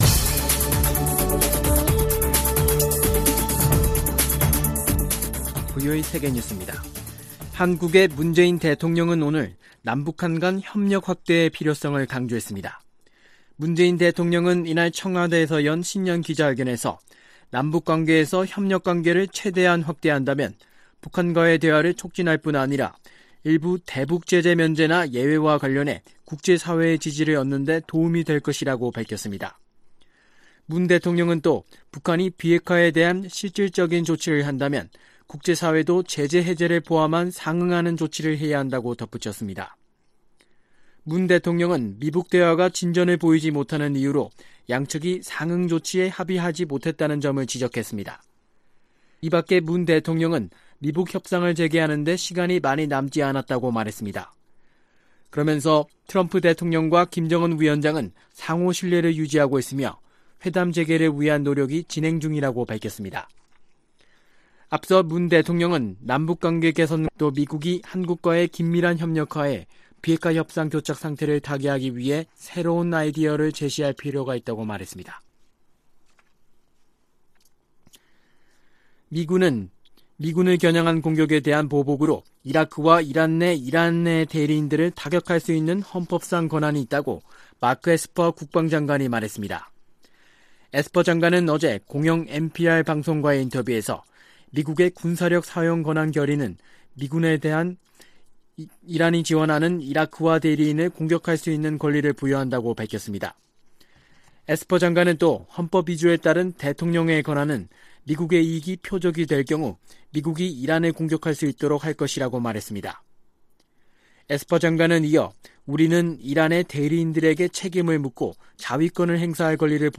VOA 한국어 간판 뉴스 프로그램 '뉴스 투데이', 2019년 1월 14일2부 방송입니다. 문재인 한국 대통령은 신년 기자회견에서 미-북 정상 간 신뢰와 대화 의지가 지속되고 있다는 점에서 아직은 긍정적이라고 평가했습니다. 미국과 한국 간 방위비 분담금 협상이 재개되는 가운데, 트럼프 대통령은 여전히 한국이 더 많이 내야한다는 입장을 고수하고 있습니다.